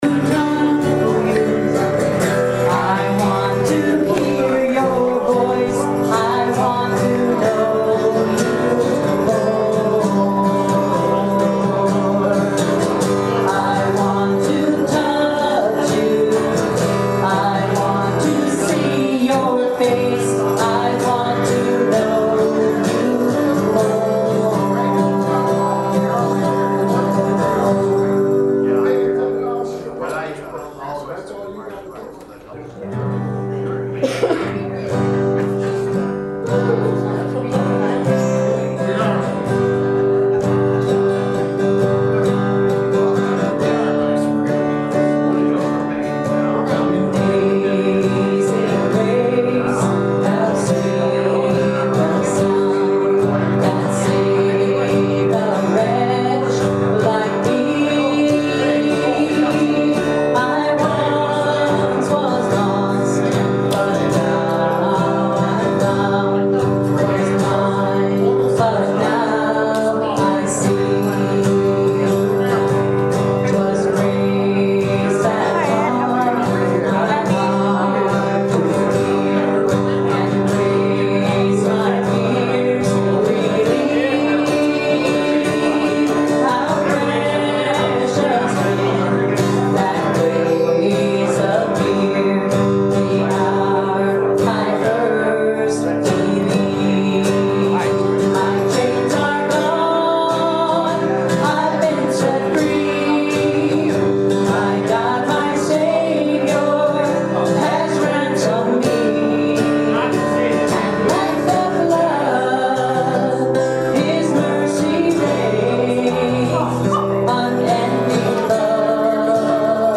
April 16th, 2017 Easter Sunday Service